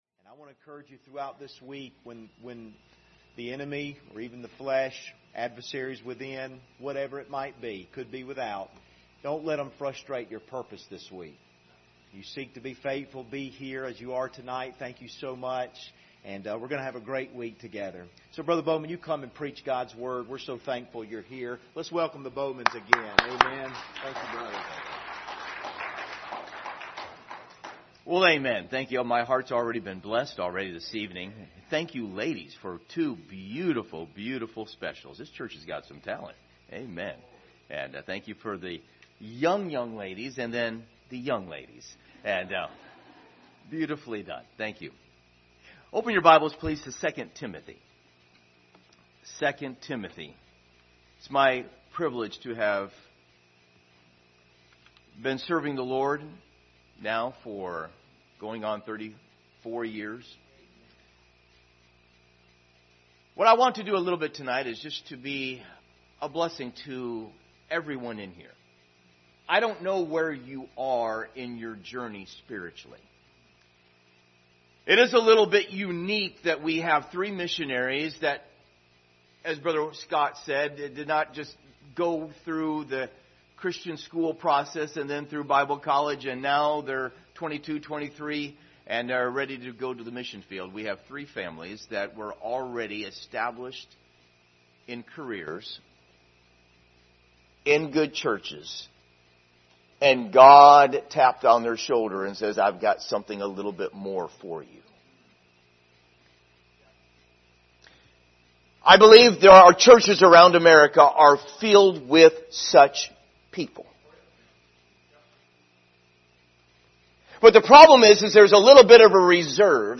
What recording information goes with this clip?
Series: 2025 Missions Conference Service Type: Sunday Evening Topics: God's faithfulness , missions